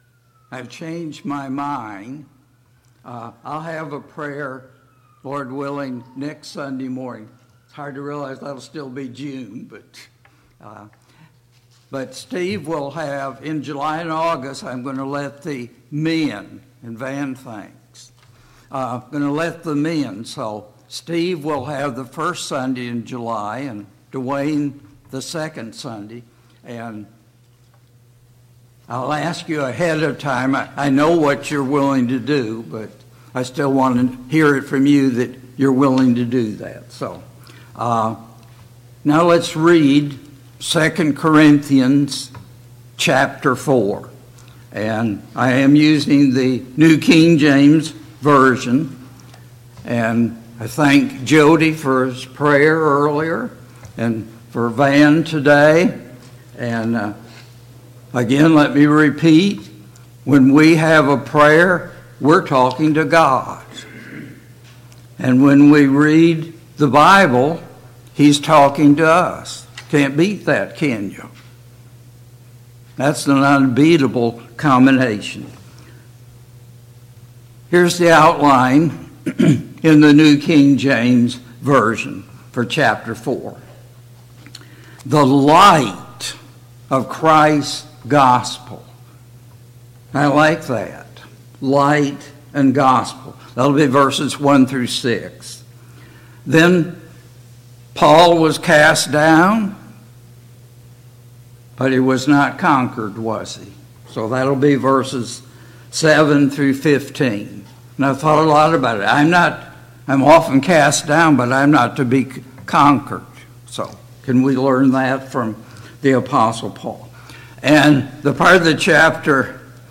2 Corinthians 4 Service Type: Sunday Morning Bible Class « 12.